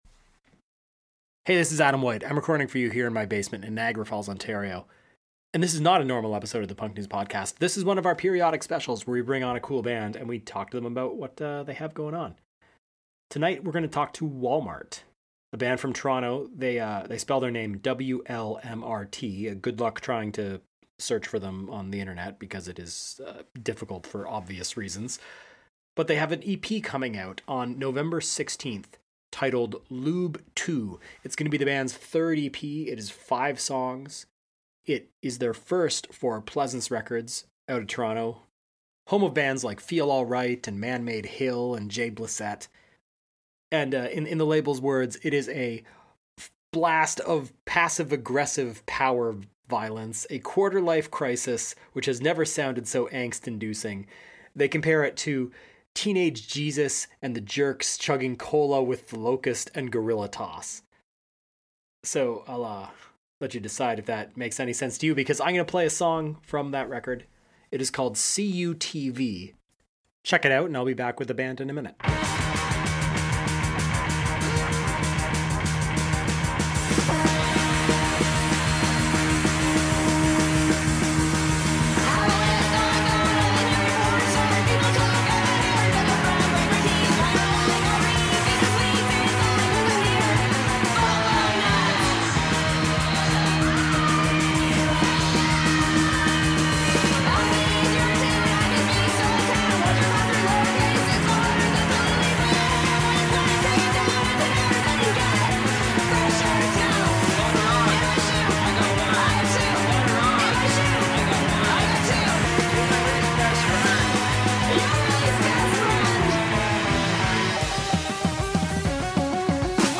Bonus - WLMRT Interview